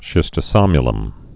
(shĭstə-sŏmyə-ləm)